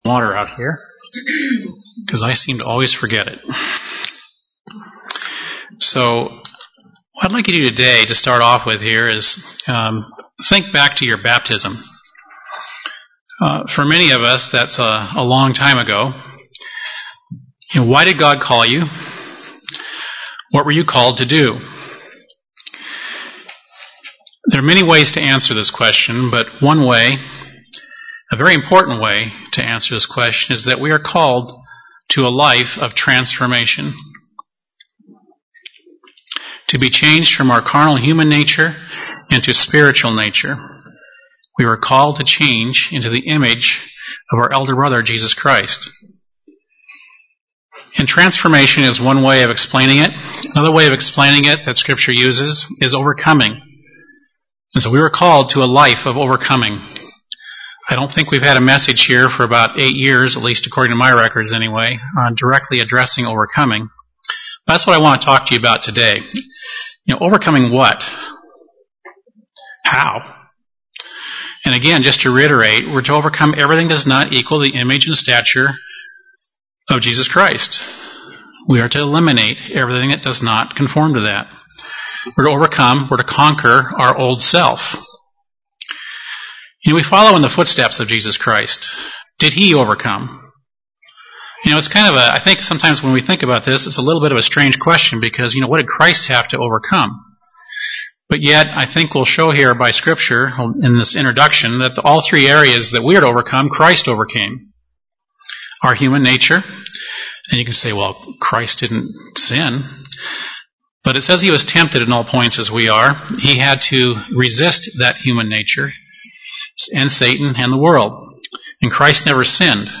Sermons
Given in Burlington, WA